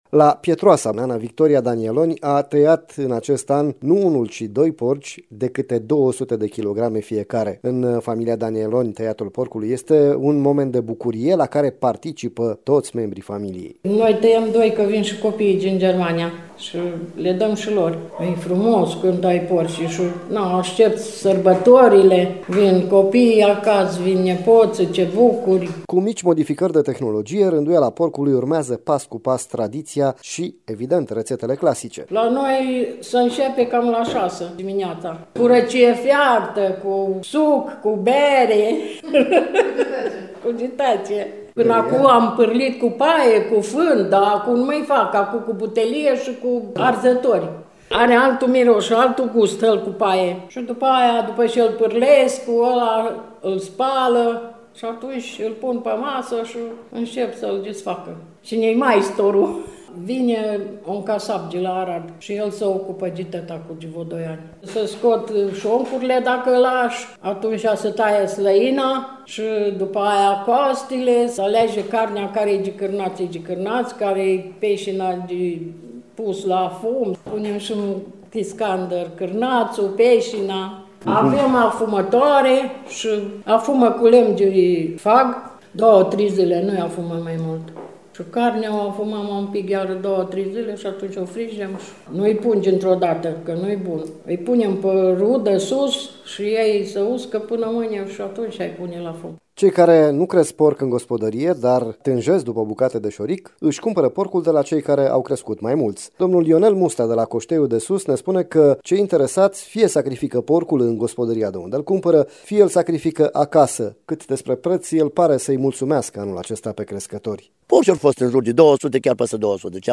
Reportaj | Sacrificarea porcului în preajma Ignatului, tradiții păstrate în Banat